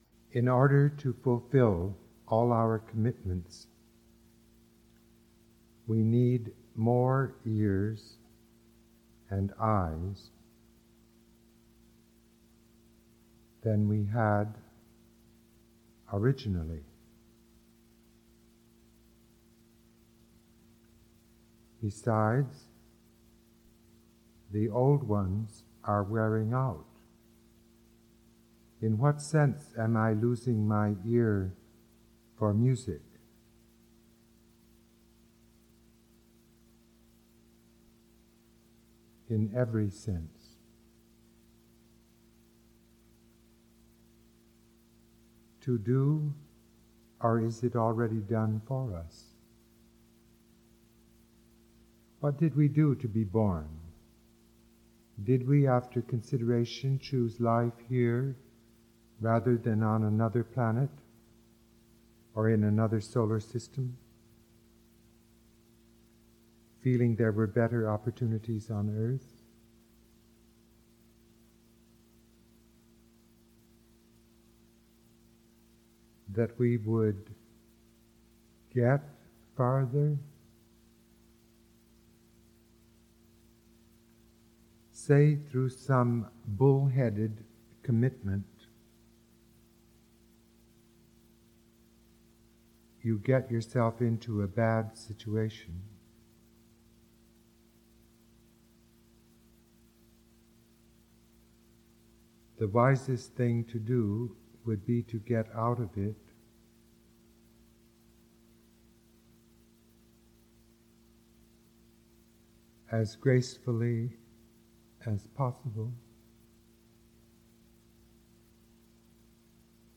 We listen to Lecture on Commitment twice – before and after words about it – and bring the program to a close with a performance of Cage’s Perpetual Tango
performed here by the pianist